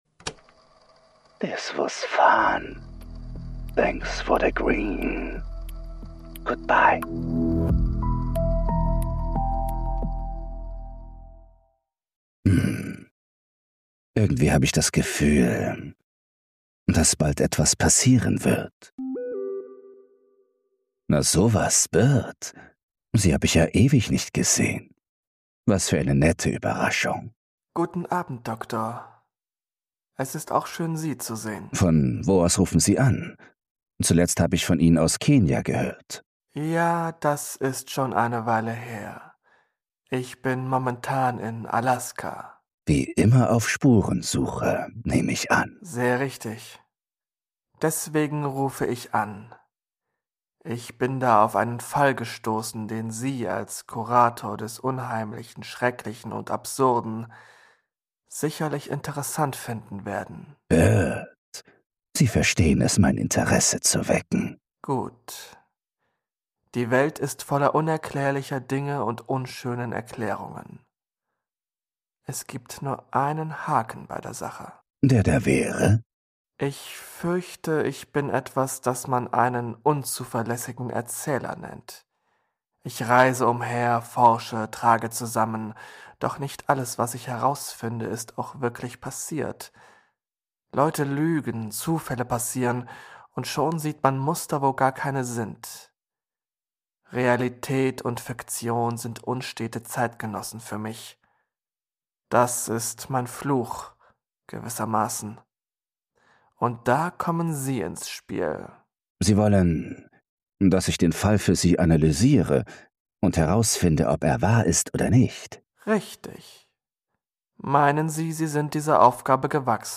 Ein deutscher Hörbuch-Podcast zwischen True Crime und Mystery – zum Miträtseln, Ablenken und Einschlafen.